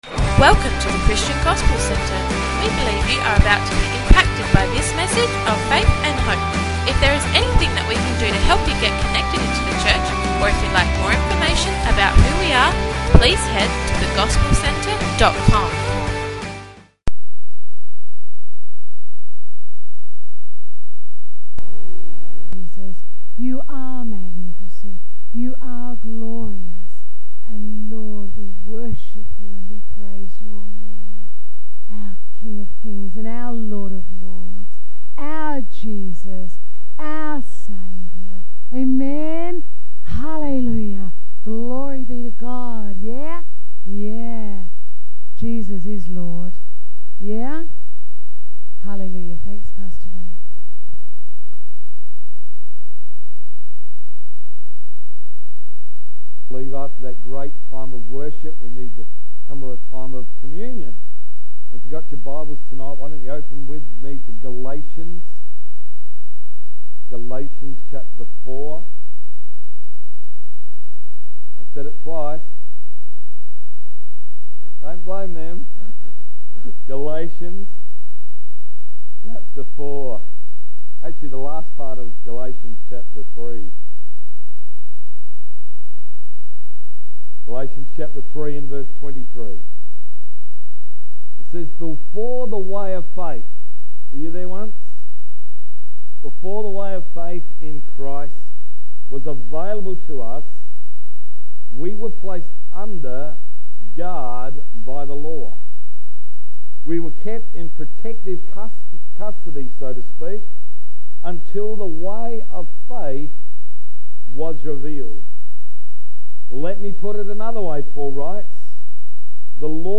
13th March 2016 – Evening Service